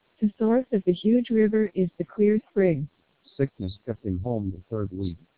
Additionally, the TWELP vocoder features an NCSE (Noise Cancellation Speech Enhancement) preprocessor, which cleans the input speech signal from noise and enhances speech quality.
Below, you can listen to a short fragment of heavily noisy English speech after passing through MELPe and TWELP vocoders, with NPP (Noise Pre-Processor) and NCSE disabled and enabled, respectively.